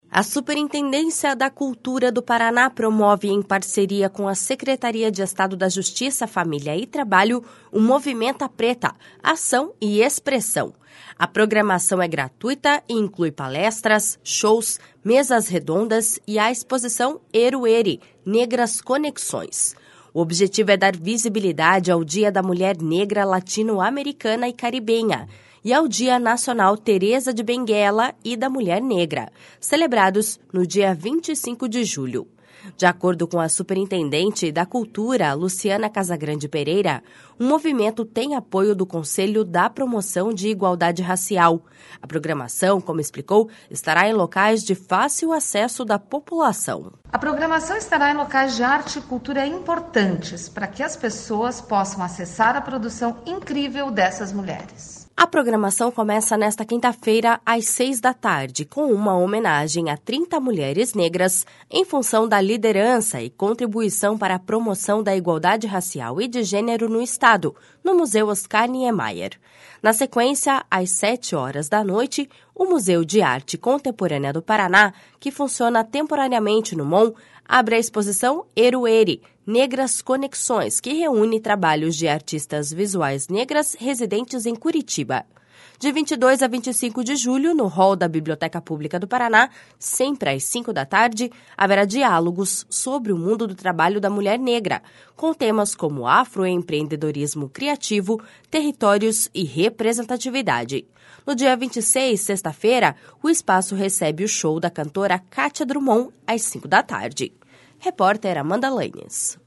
De acordo com a superintendente da Cultura, Luciana Casagrande Pereira, o movimento tem apoio do Conselho da Promoção de Igualdade Racial. A programação, como explicou, estará em locais de fácil acesso da população.// SONORA LUCIANA CASAGRANDE.//